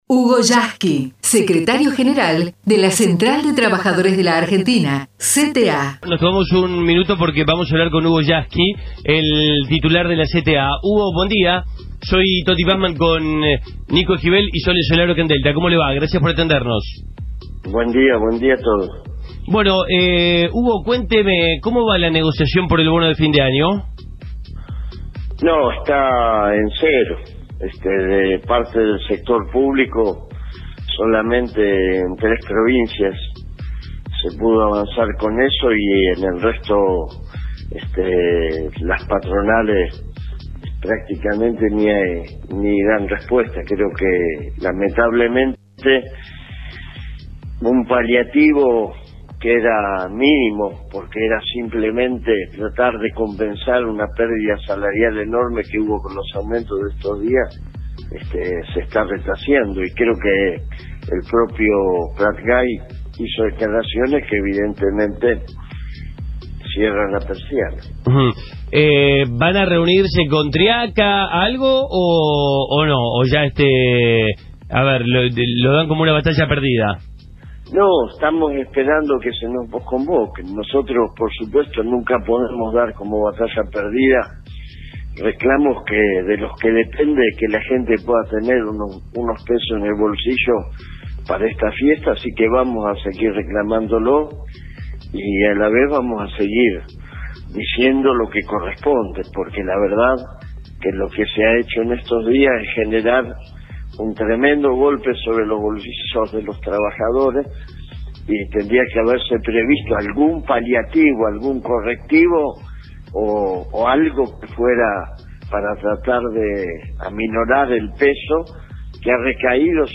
HUGO YASKY (entrevista) TOTI PASMAN // radio FM DELTA